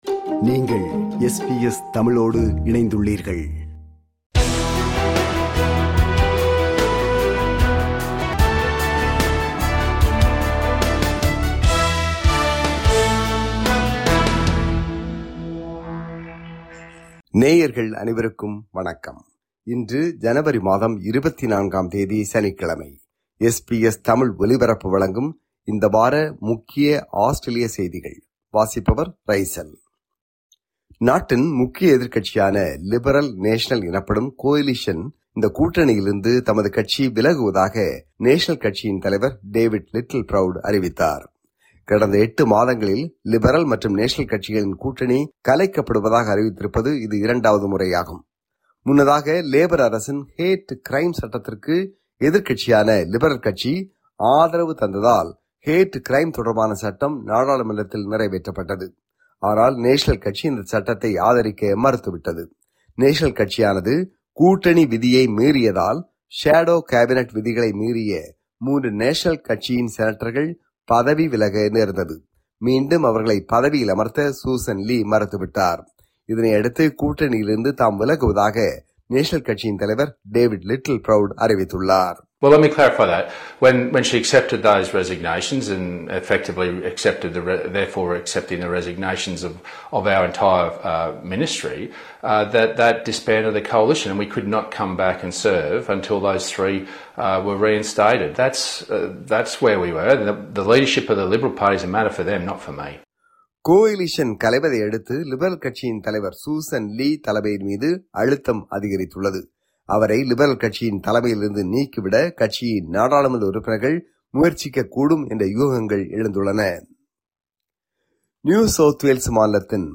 ஆஸ்திரேலியாவில் இந்த வாரம் (18 – 24 ஜனவரி 2026) நடந்த முக்கிய செய்திகளின் தொகுப்பு.